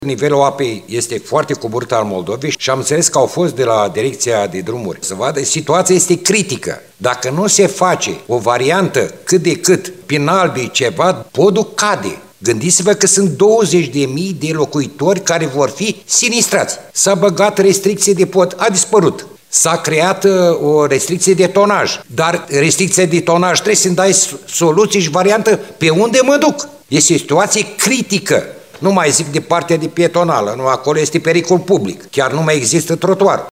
În ședința legislativului, el a spus că atât pilonii, cât și structura podului sunt puternic afectate.